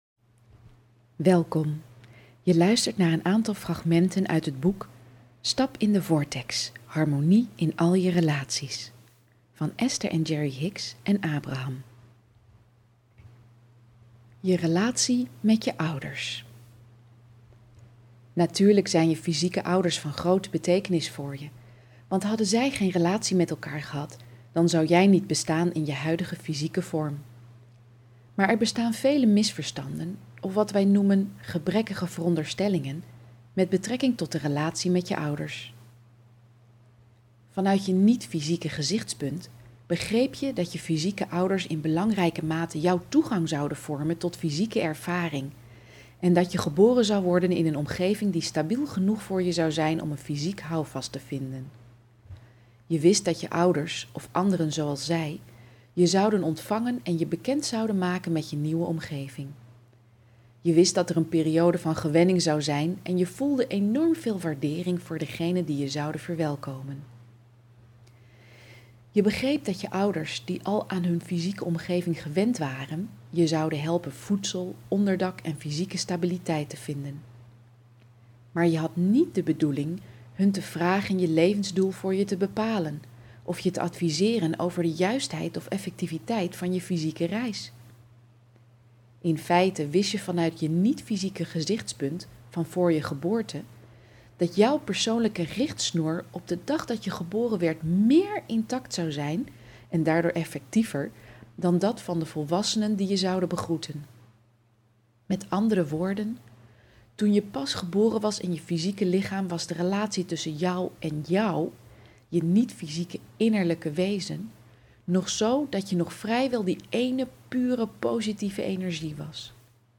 Luisterboek
Luisterboek met fragmenten uit het boek 'Stap in de Vortex - Harmonie in al je relaties'.